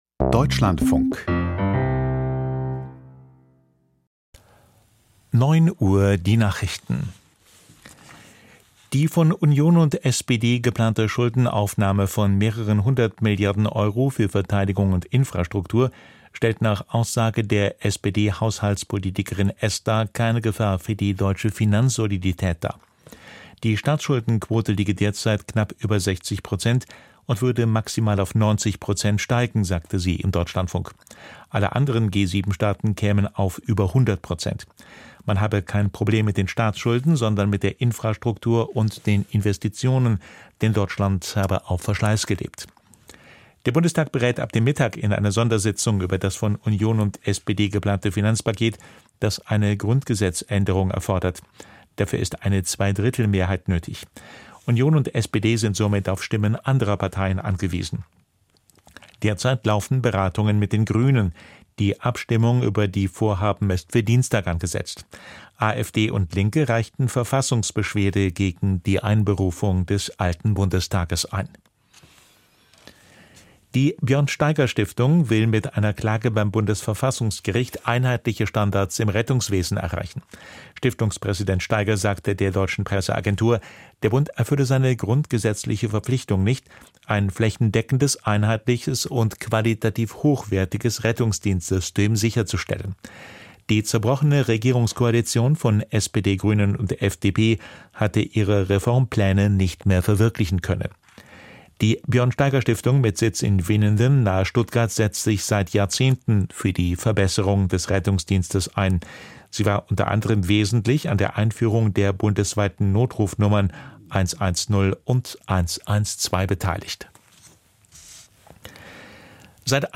Die Deutschlandfunk-Nachrichten vom 13.03.2025, 09:00 Uhr
Aus der Deutschlandfunk-Nachrichtenredaktion.